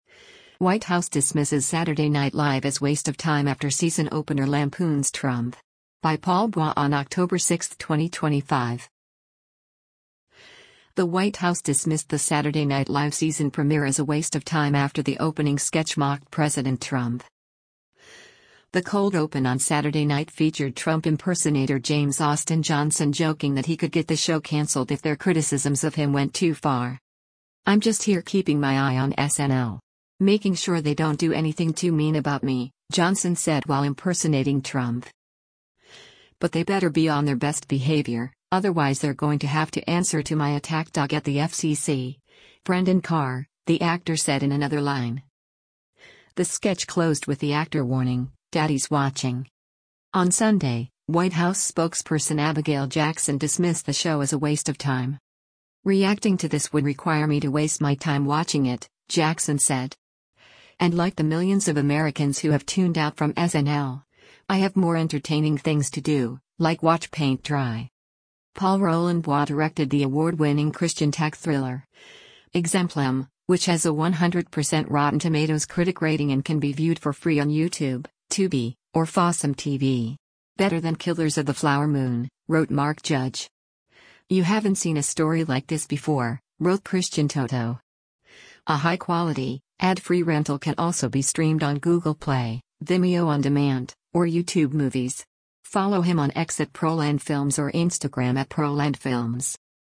The cold open on Saturday night featured Trump impersonator James Austin Johnson joking that he could get the show canceled if their criticisms of him went too far.